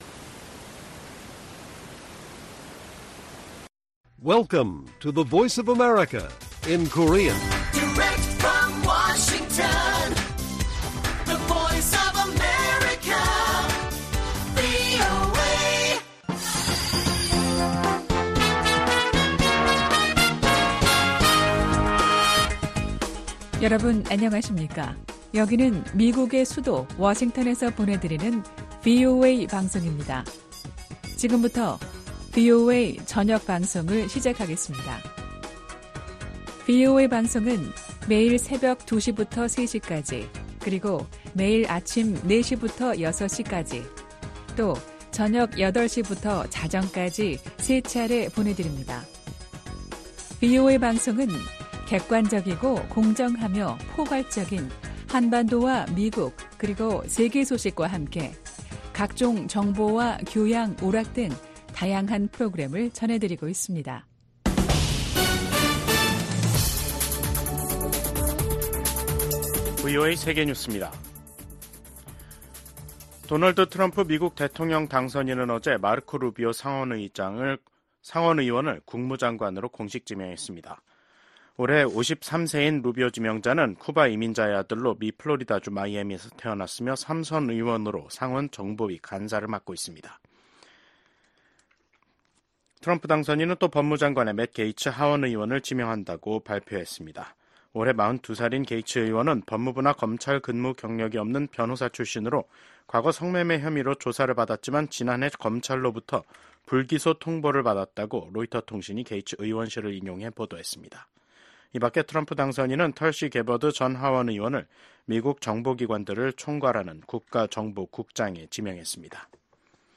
VOA 한국어 간판 뉴스 프로그램 '뉴스 투데이', 2024년 11월 13일 1부 방송입니다. 조 바이든 미국 대통령과 도널드 트럼프 대통령 당선인이 백악관에서 회동했습니다. 미국 백악관 국가안보보좌관은 북한군의 러시아 파병 문제가 차기 트럼프 행정부로 이어질 미국의 주요 안보 위협 중 하나라고 밝혔습니다.